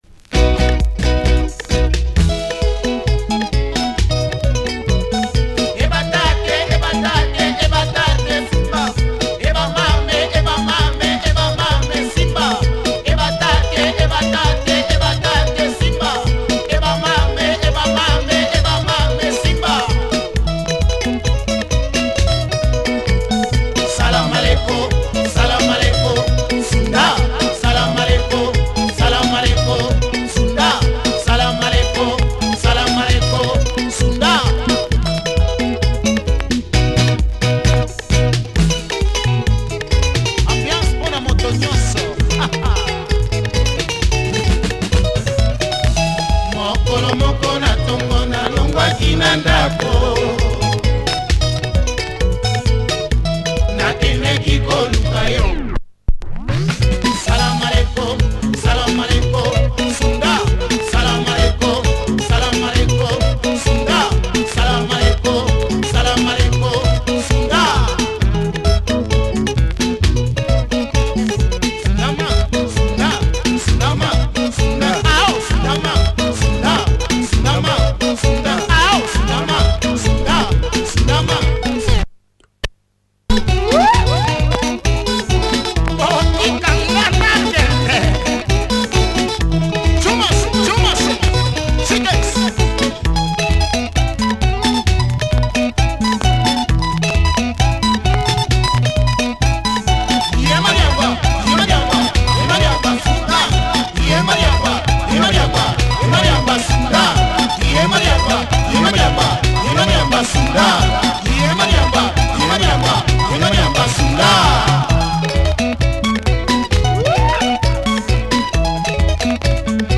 Punchy 80's Lingala.